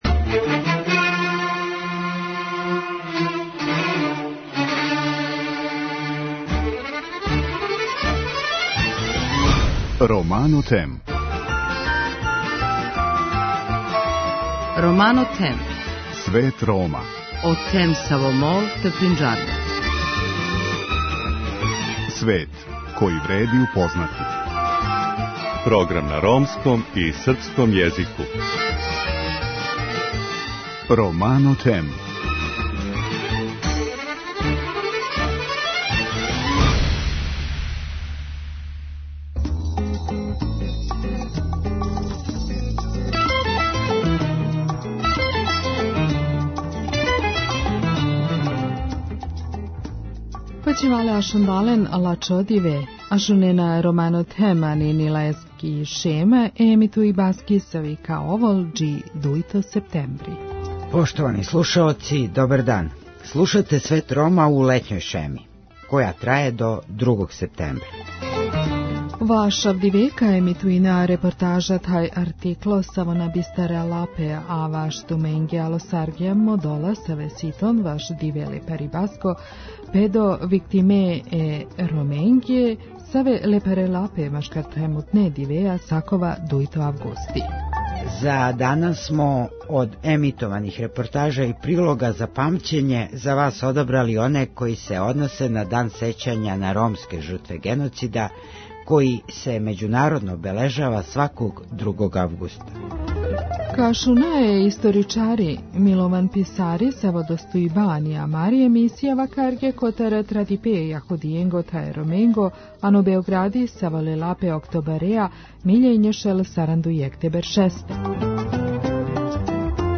У другом делу емисије емитујемо разговор са представницима немачке Фондације - Сећање, одговорност, будућност који су недавно боравили у Београду.